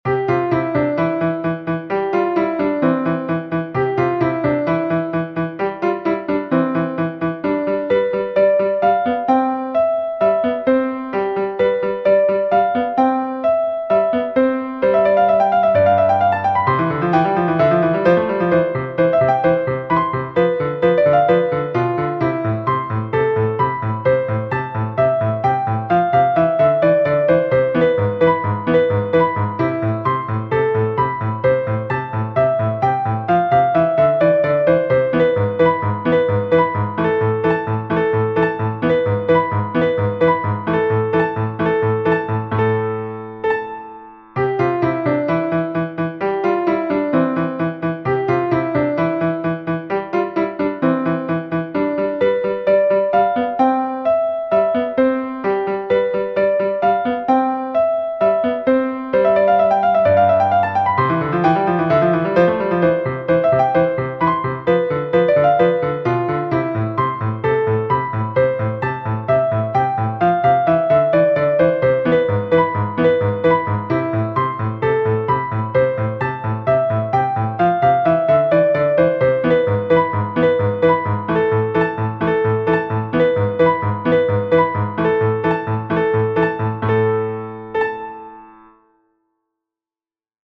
Piano #133 - Piano Music, Solo Keyboard - Young Composers Music Forum